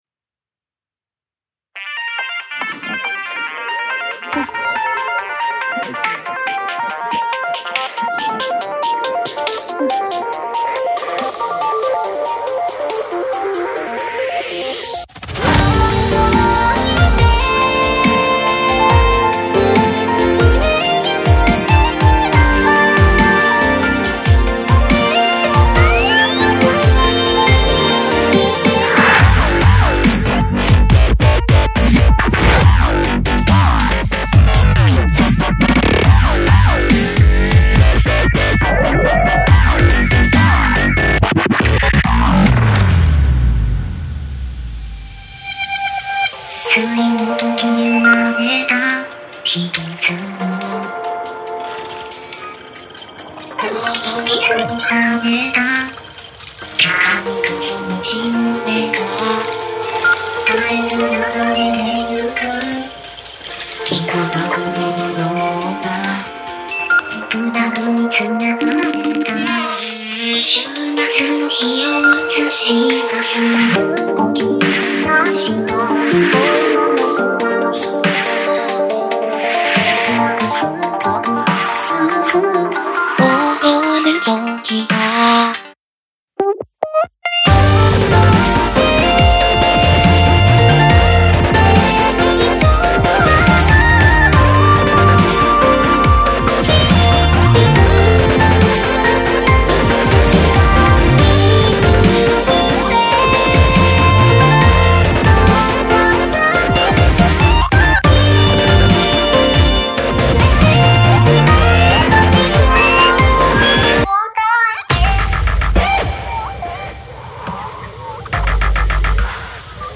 エレクトロ系のシンセサウンドを使用したデジタリックな楽曲になっています。